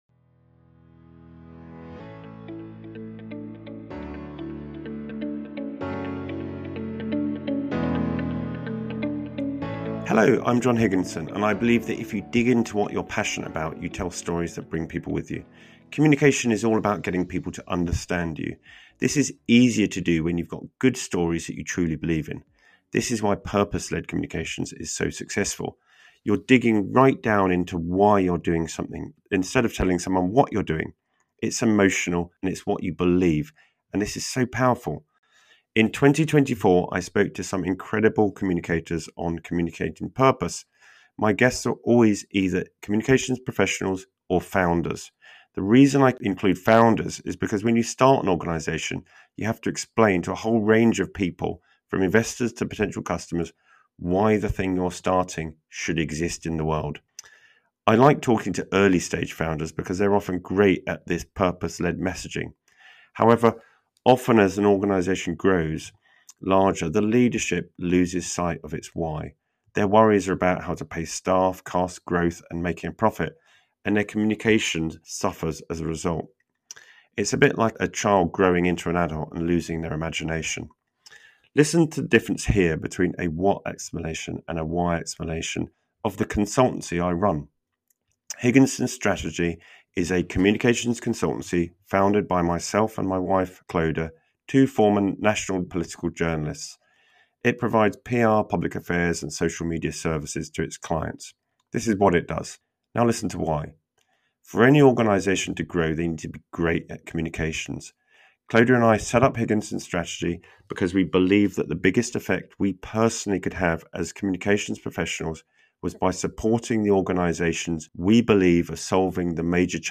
This special compilation highlights some of my favourite moments with accomplished purpose-driven leaders, with anecdotes and actionable wisdom from some of the most visionary communicators and founders of our time.